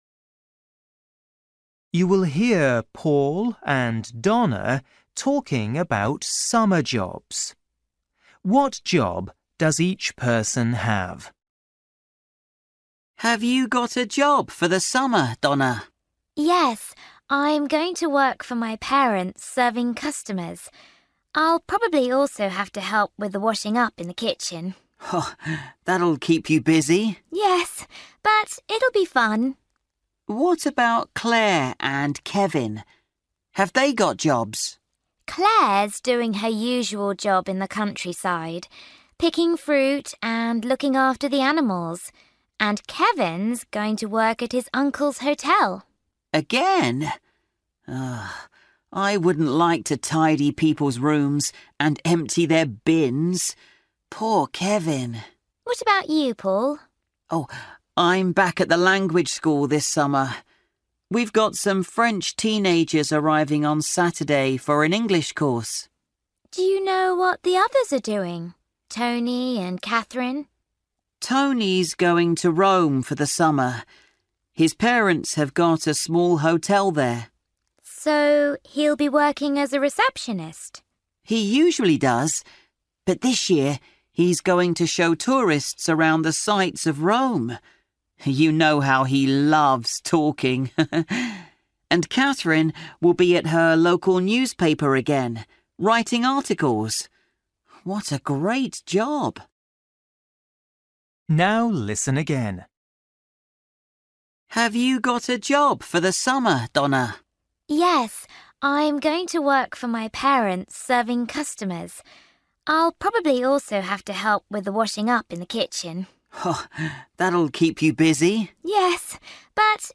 Luyện nghe trình độ A2